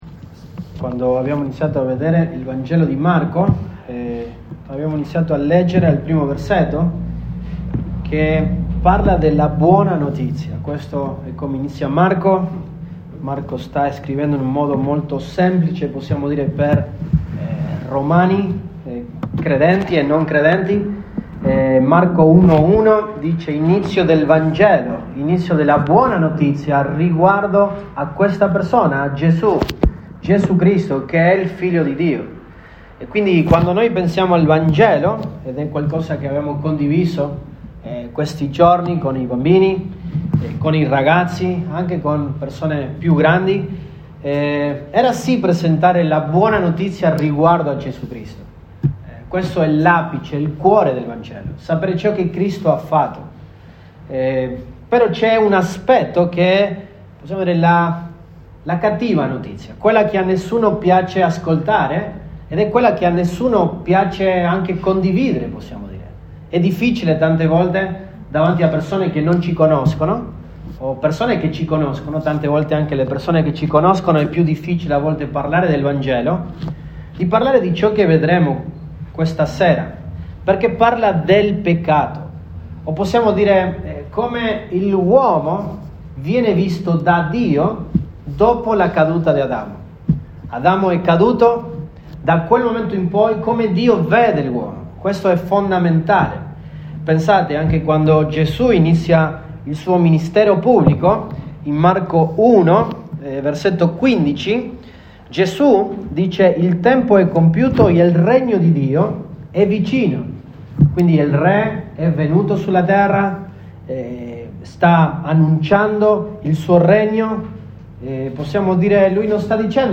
Giu 29, 2022 Come Dio vede l’uomo dopo la caduta di Adamo MP3 Note Sermoni in questa serie Come Dio vede l'uomo dopo la caduta di Adamo.